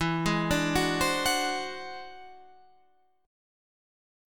E Augmented 9th